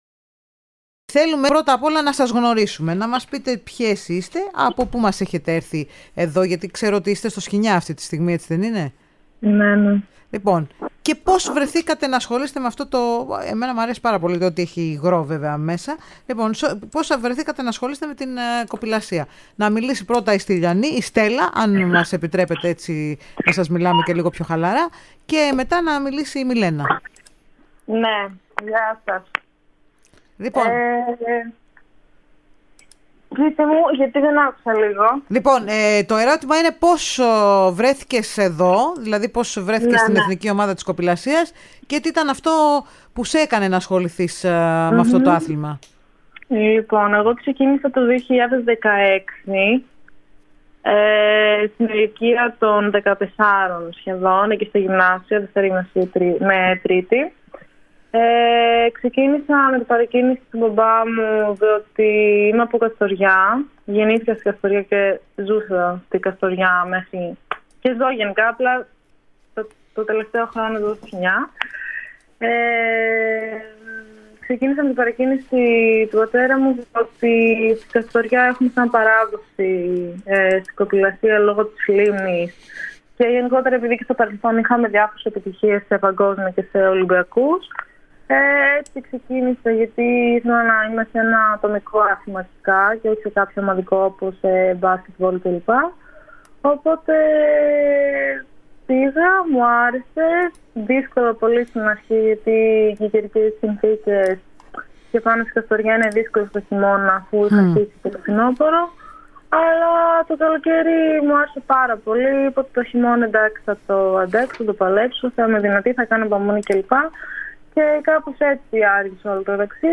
Οι “χρυσές” αθλήτριες, μίλησαν στην ΕΡΑ Σπορ